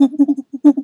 Animal_Impersonations
monkey_2_chatter_16.wav